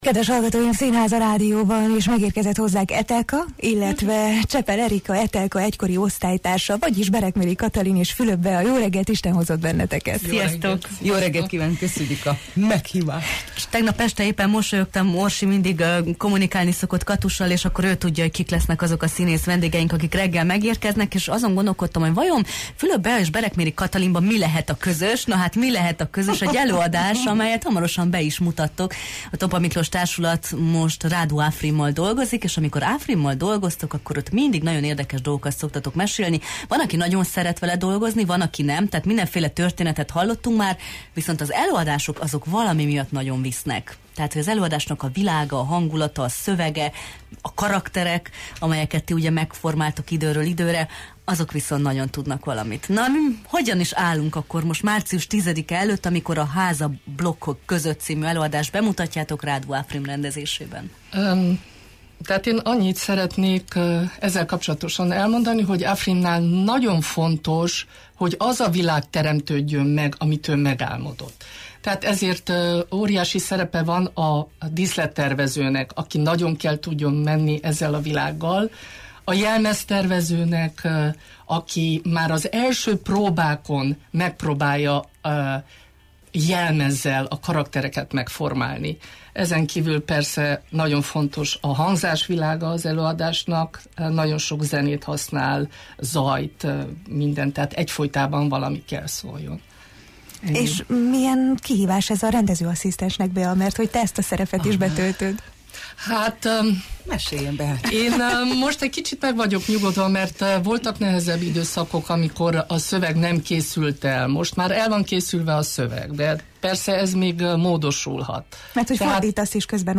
színművészek voltak a Jó reggelt, Erdély! vendégei: